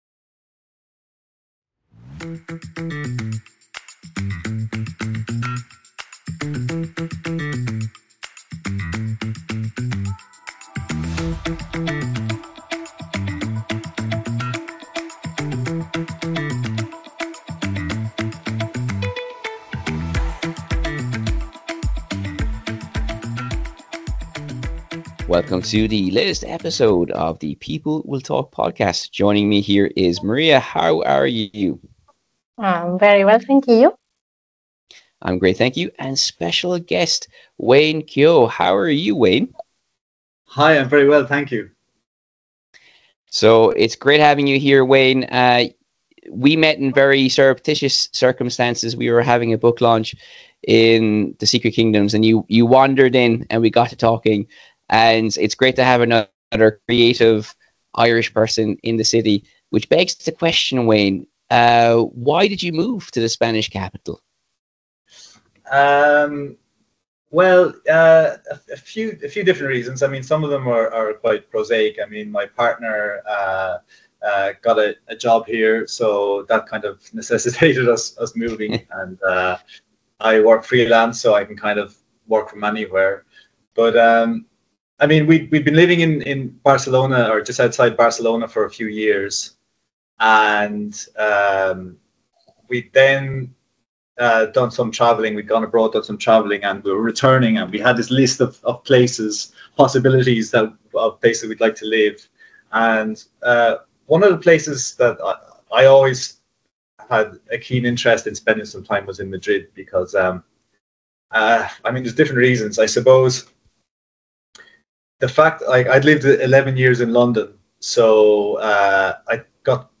Interviewing a World Traveller and Designer